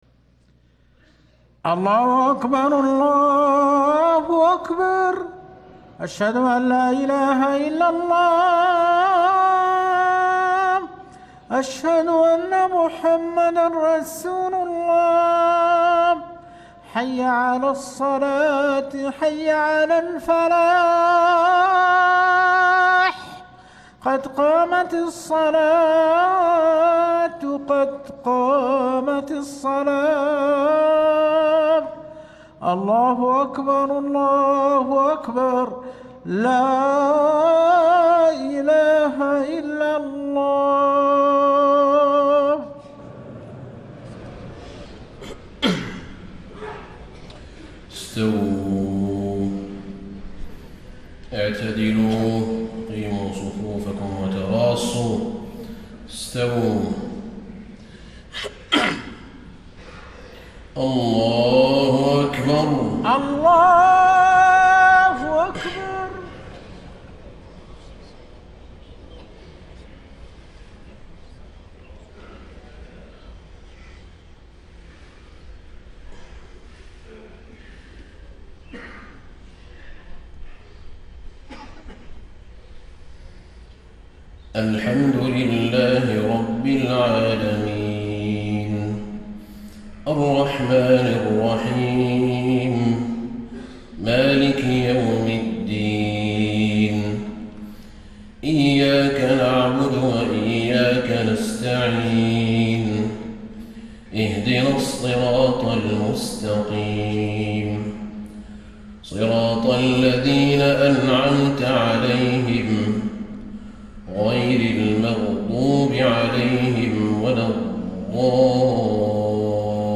فجر 21 شعبان ١٤٣٥ من سورة المؤمنون > 1435 🕌 > الفروض - تلاوات الحرمين